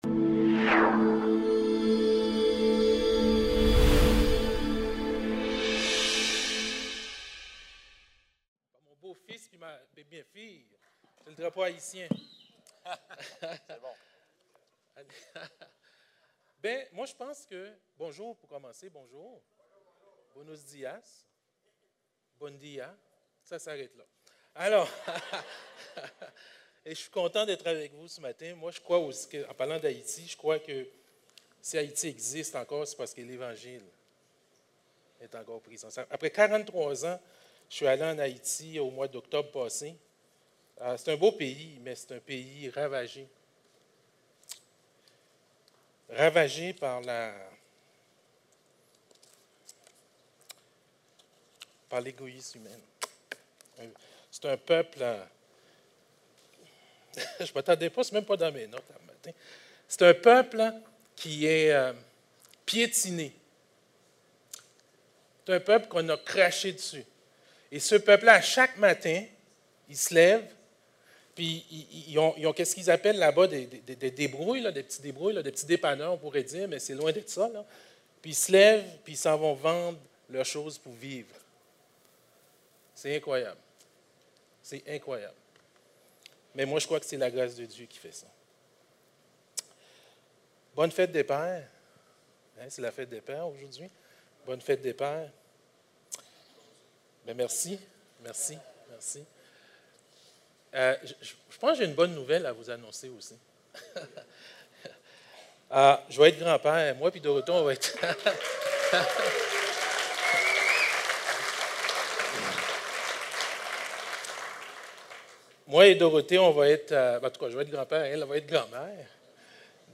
Service multiculturel - Fête des pères < église le Sentier | Jésus t'aime!